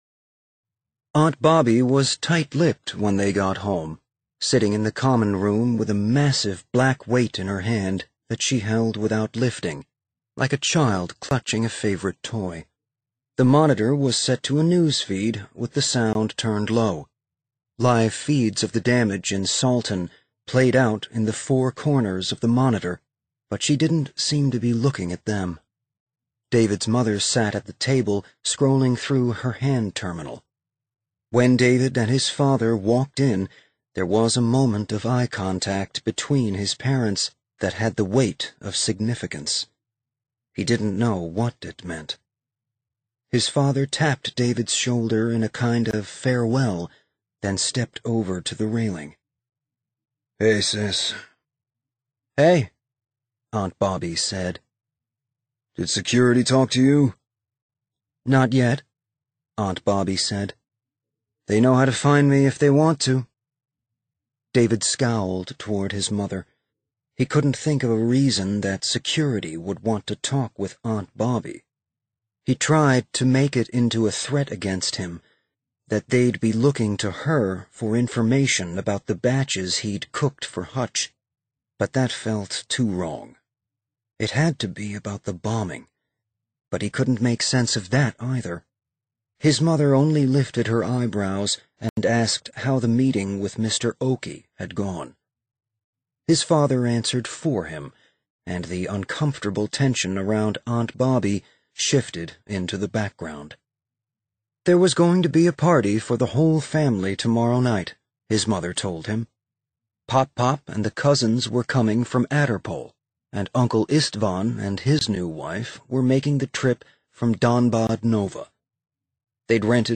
drop/books/audiobooks/James S. A. Corey